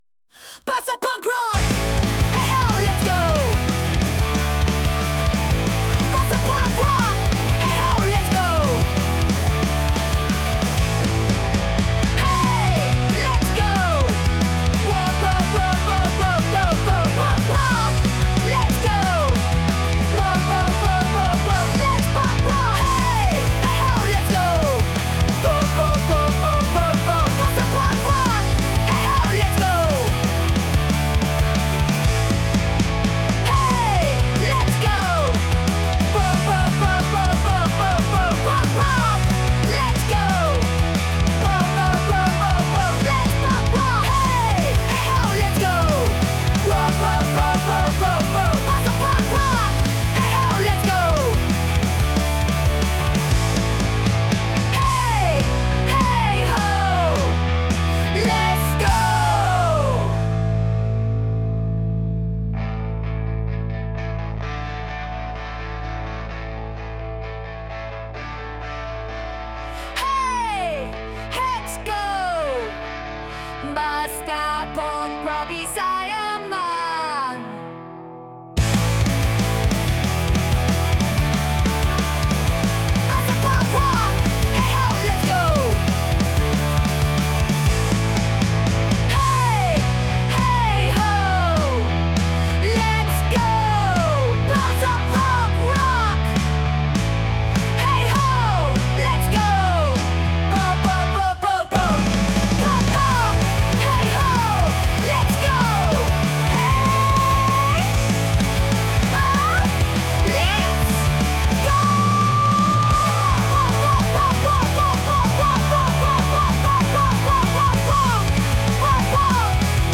English, Punk, Metal, Rock | 18.04.2025 12:47